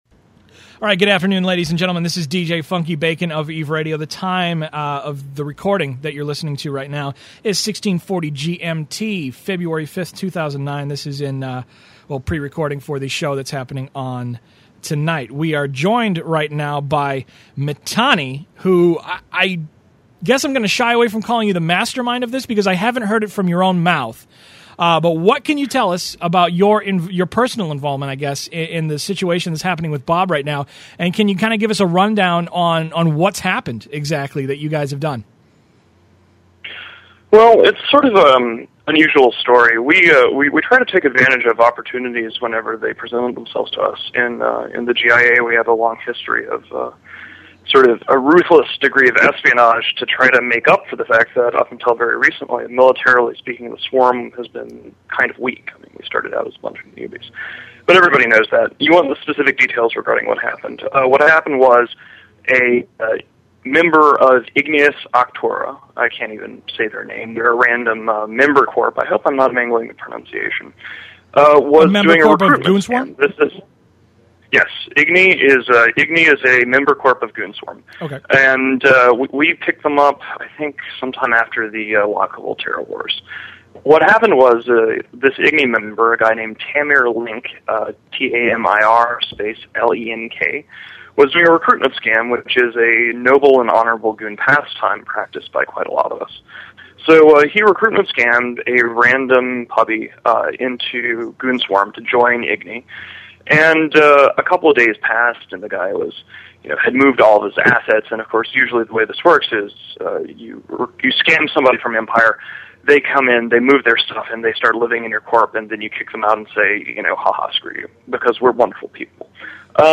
A good interview with the guy who pulled it off: http